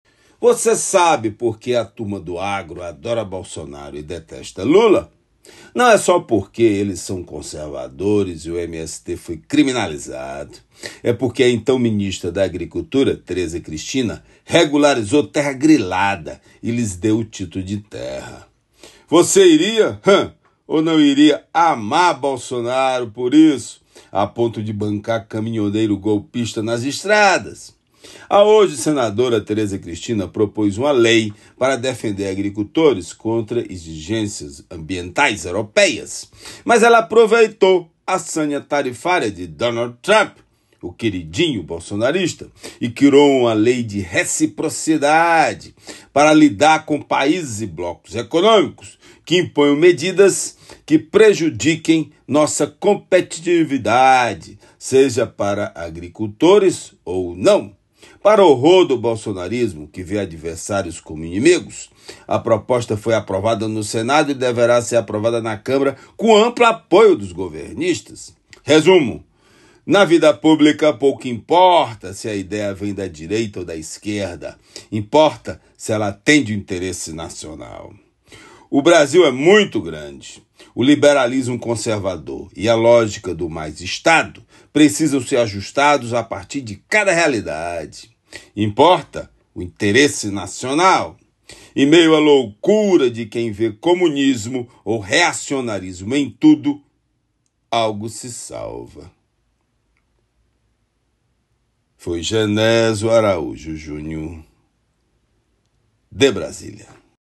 Comentário do jornalista